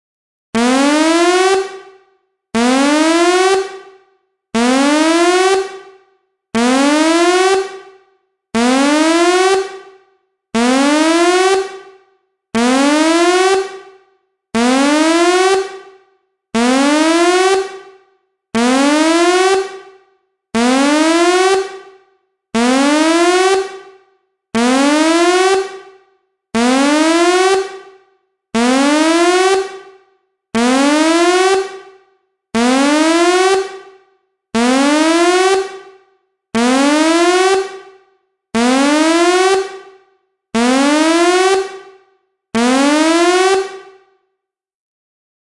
Download Alert sound effect for free.
Alert